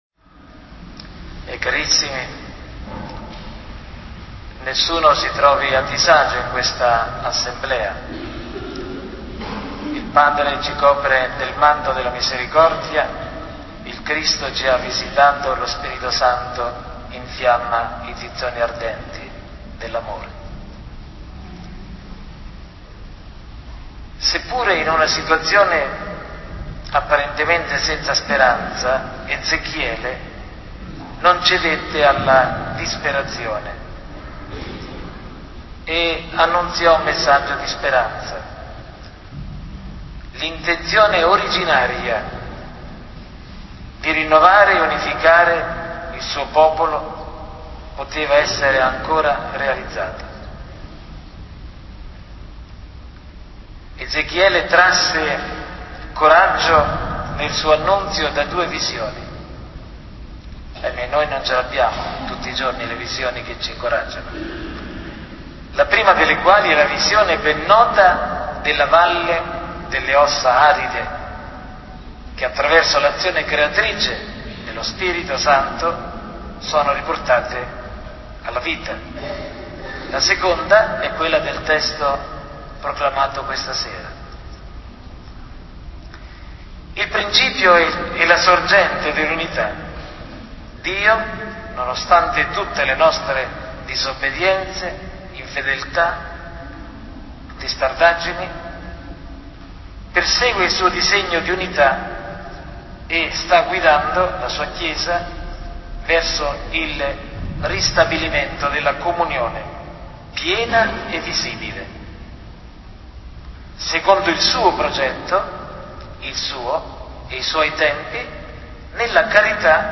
veglia-ecumenica-gennaio-2009.mp3